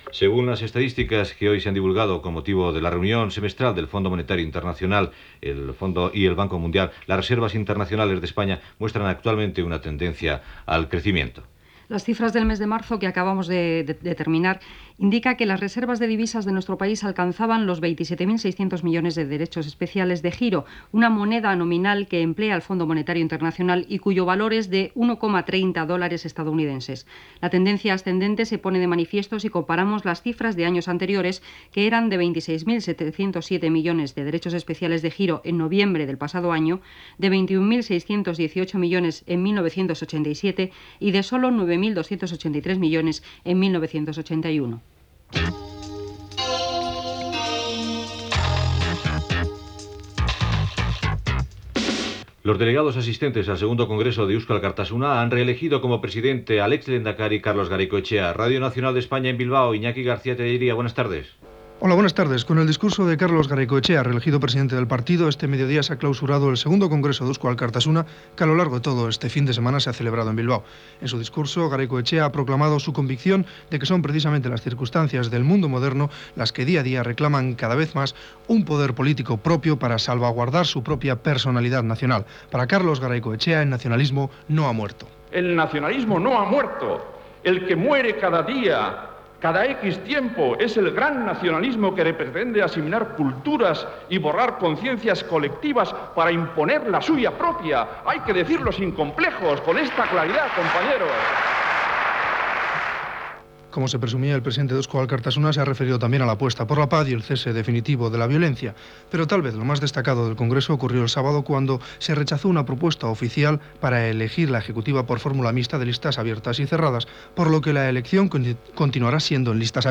Rerservas de divises a Espanya, segon congrés d'Eusko Alkartasuna, congrés del Partit dels Comunistes de Catalunya, congrés de Coalición Gallega, consell nacional de Convergència Democràtica de Catalunya, canvi de posicionament de la banda ETA, declaracions de Santiago Carrillo sobre ETA, Gibraltar, relacions centre Amèrica i Europa, Arafat escollit president de l'Estat palestí a l'exili. El temps, comiat i sintonia de l'emissora Gènere radiofònic Informatiu